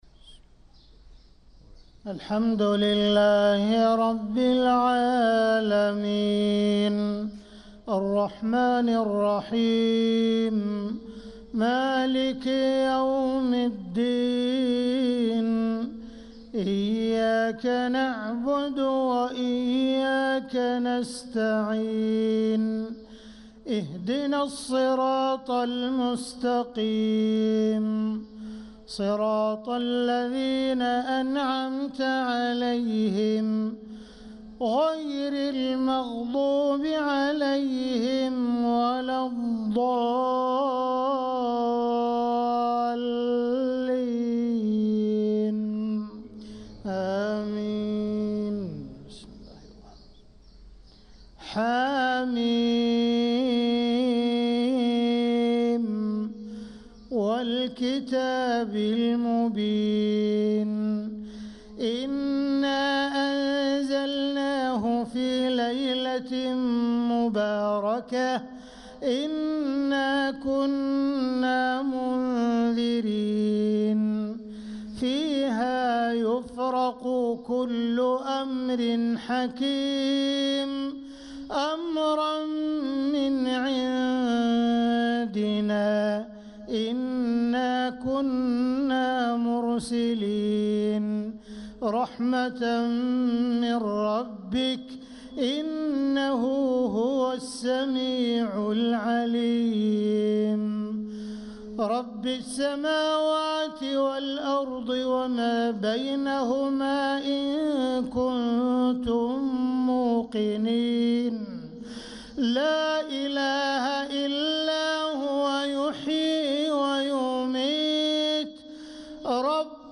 صلاة الفجر للقارئ عبدالرحمن السديس 20 رجب 1446 هـ
تِلَاوَات الْحَرَمَيْن .